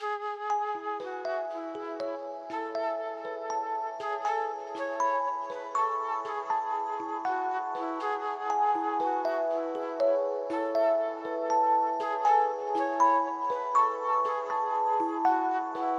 Tag: 120 bpm Trap Loops Synth Loops 2.69 MB wav Key : E Cubase